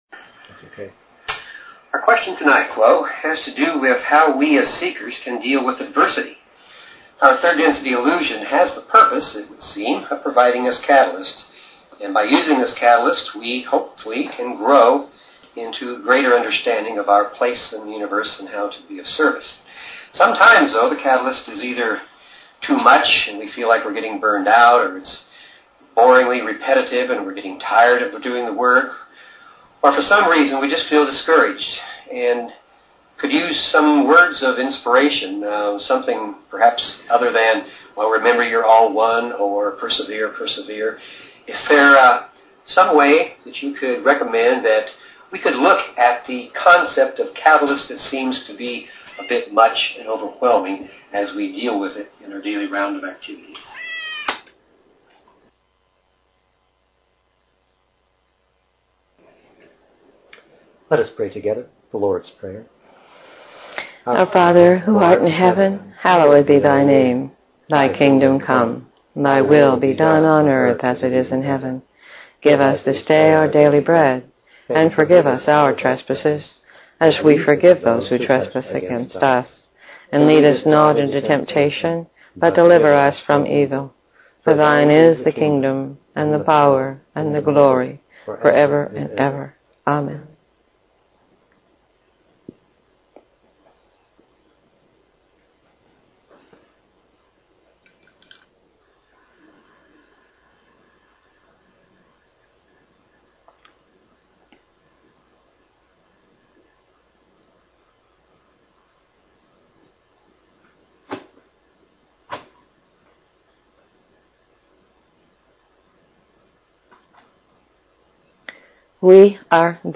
Talk Show Episode, Audio Podcast, LLResearch_Quo_Communications and Courtesy of BBS Radio on , show guests , about , categorized as